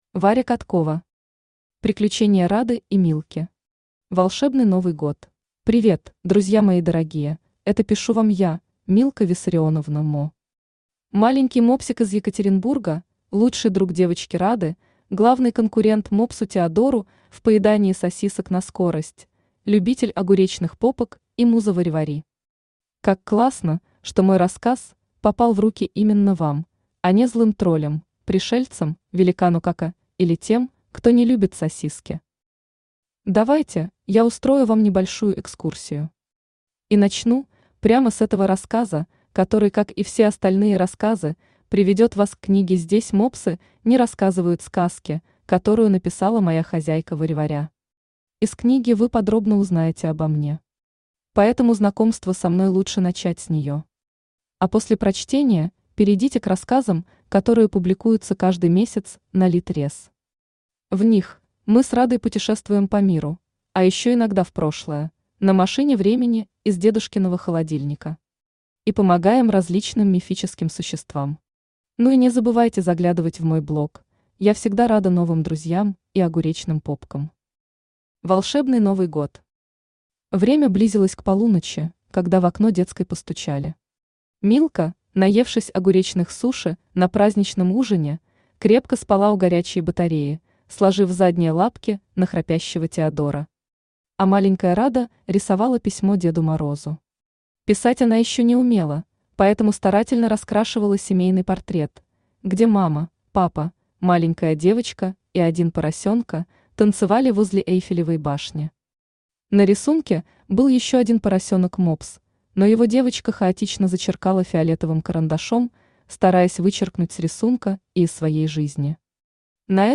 Аудиокнига Приключения Рады и Милки. Волшебный Новый год | Библиотека аудиокниг
Волшебный Новый год Автор Варя Каткова Читает аудиокнигу Авточтец ЛитРес.